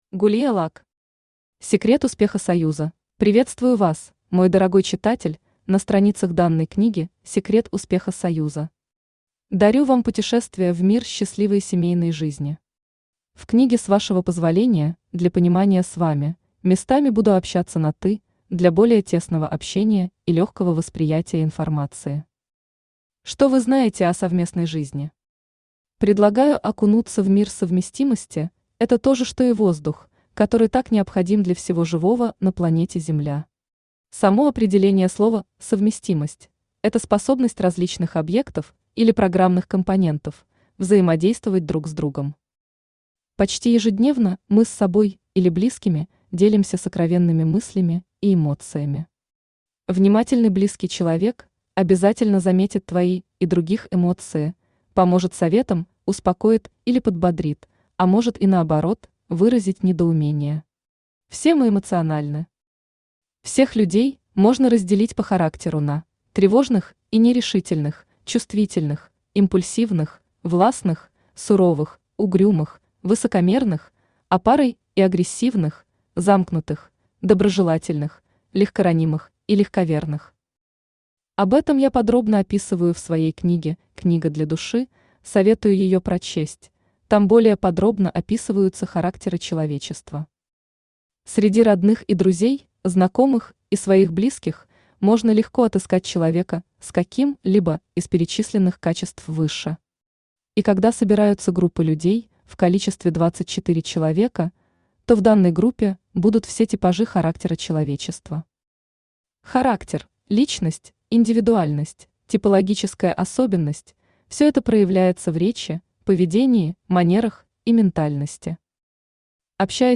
Аудиокнига Секрет успеха союза!
Автор Gulia Luck Читает аудиокнигу Авточтец ЛитРес.